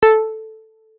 notification_sounds